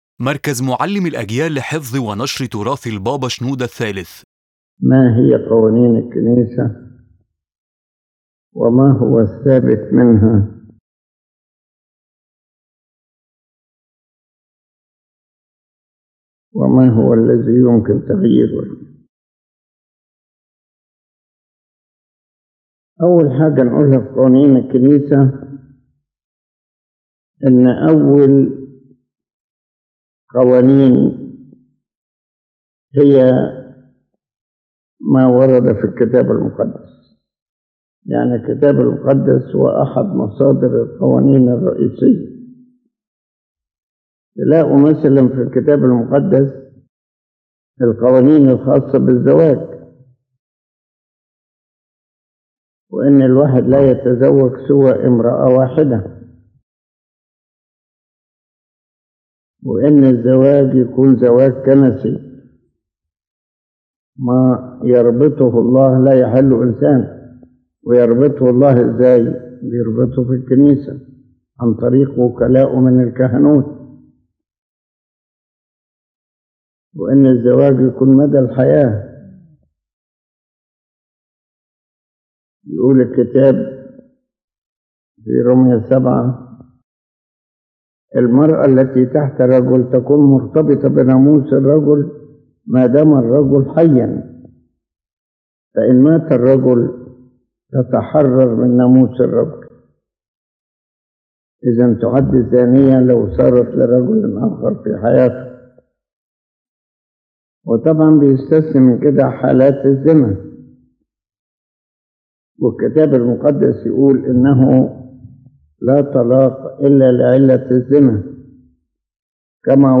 ⬇ تحميل المحاضرة The Pope begins by clarifying that the first source of the Church’s laws is the Holy Bible , where there are clear texts on marriage, divorce, and spiritual matters.